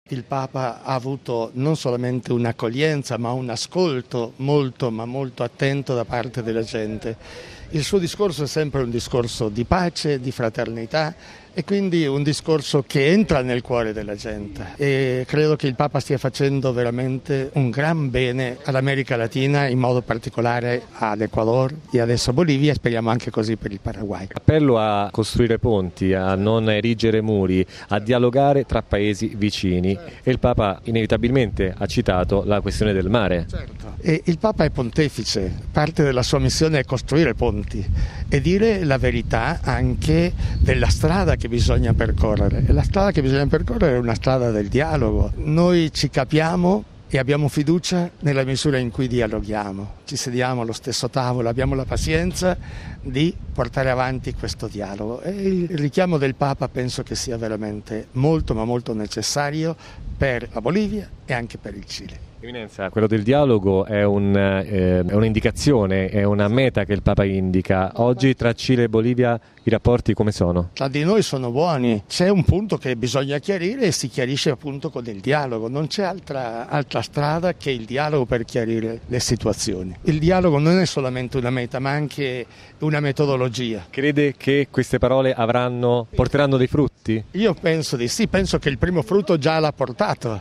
Ai nostri microfoni, il porporato riflette in particolare sulla questione dello sbocco al mare che la Bolivia ha perso nel passato e che vede un contenzioso con il Cile.